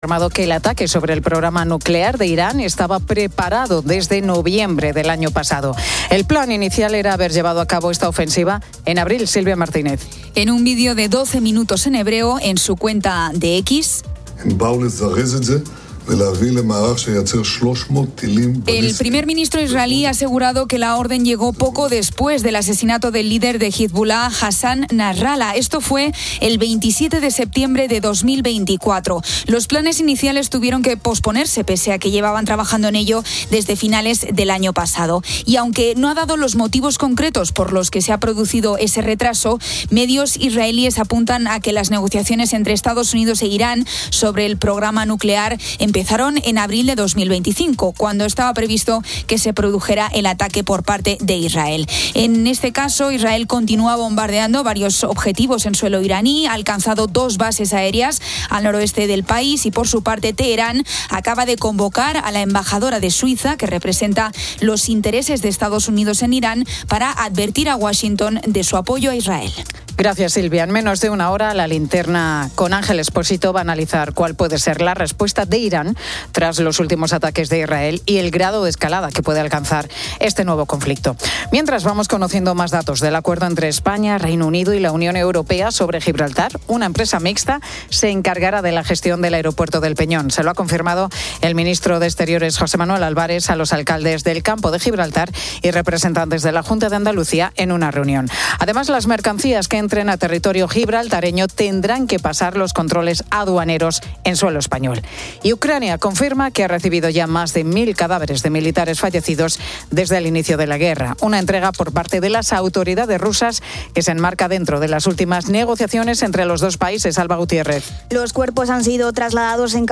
La Tarde 18:00H | 13 JUN 2025 | La Tarde Pilar García Muñiz entrevista a la periodista y escritora, Nativel Preciado. También se desplaza hasta Estados Unidos para conocer cómo se va a vivir el nuevo Mundial de Clubes.